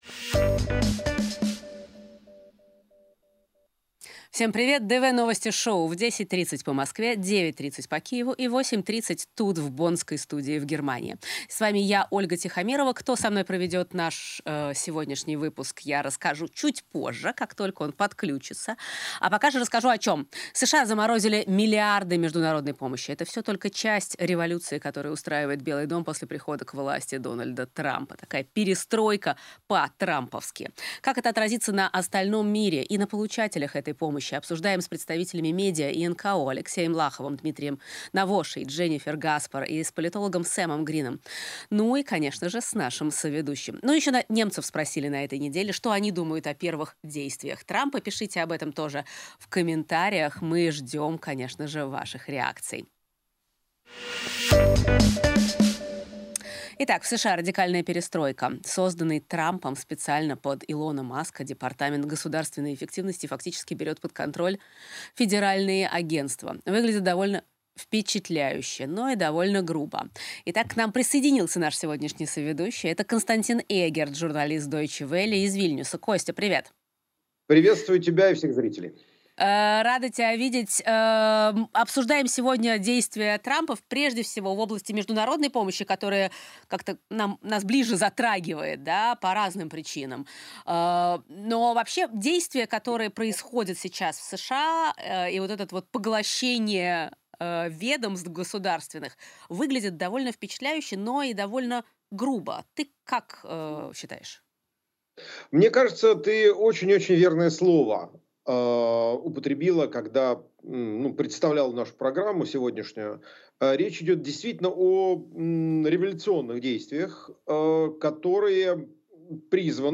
"DW Новости Шоу" выходит еженедельно по средам в 19.30 по московскому времени (17.30 - по Берлину) в прямом эфире на YouTube-канале "DW на русском".